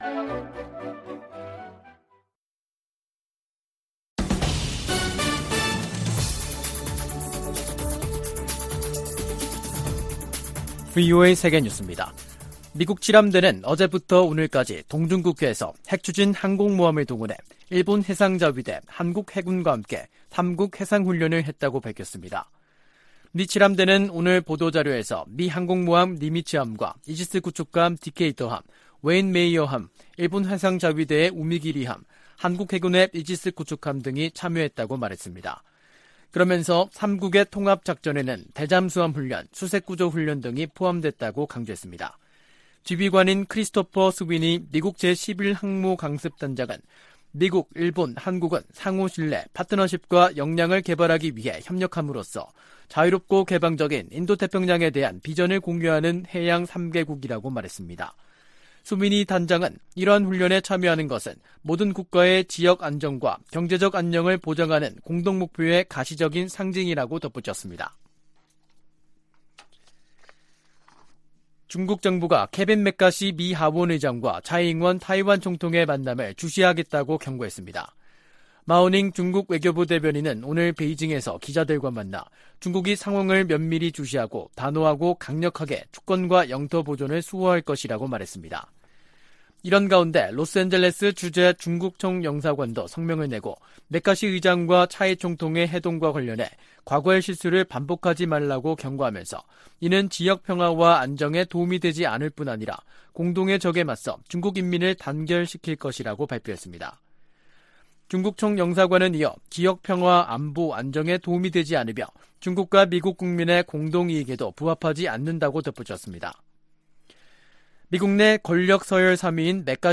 VOA 한국어 간판 뉴스 프로그램 '뉴스 투데이', 2023년 4월 4일 3부 방송입니다. 미 국방부는 북한 핵실험을 계속 감시하고 있으며, 궁극적인 목표는 여전히 한반도 비핵화라고 거듭 확인했습니다. 4월 중 북한에 여러가지 행사들이 이어진 가운데, 미한 정상회담 등을 계기로 대형 도발 가능성도 제기되고 있습니다. 백악관 국가안보보좌관과 신임 한국 대통령실 국가안보실장이 첫 전화 통화를 갖고 미한동맹 강화를 위한 협력을 다짐했습니다.